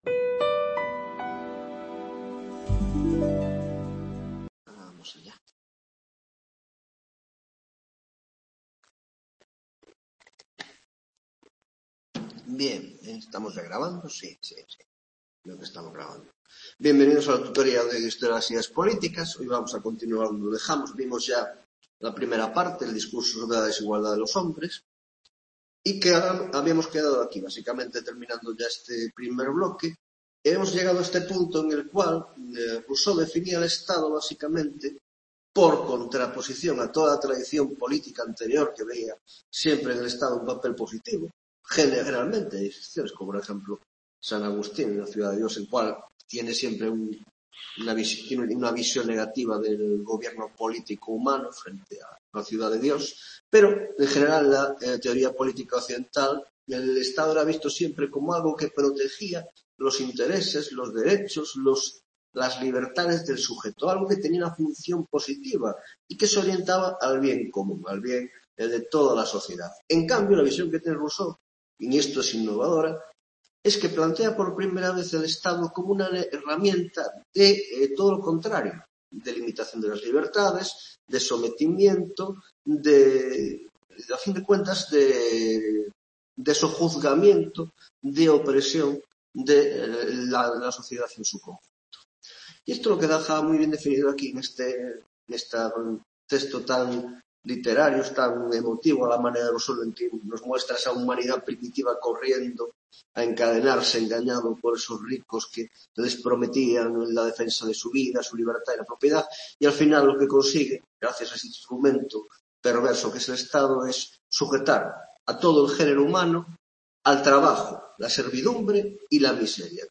8ª Tutorìa de Historia de las Ideas Políticas (Grado de Ciencias Políticas y Grado de Sociología) - Rousseau y la Idea de Democracia (2ª parte): 1) El Contrato Social: 1.1) Diferencias y similitudes entre el Contrato y el Discurso; 1.2) Funciones del Estado y sociedad política según el Contrato Social (Libertad, Igualdad y Propiedad)